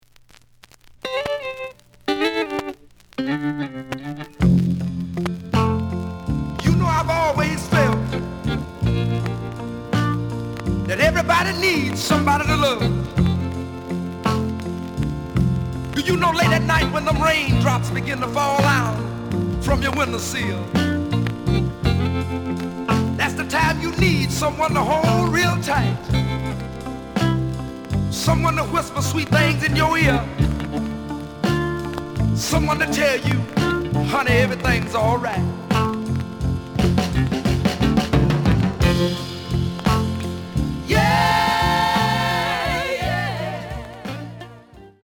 The audio sample is recorded from the actual item.
●Genre: Soul, 60's Soul
Some click noise on both sides due to scratches.)